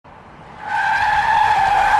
Vase Shattering
# vase # shatter # ceramic # break About this sound Vase Shattering is a free sfx sound effect available for download in MP3 format.
562_vase_shattering.mp3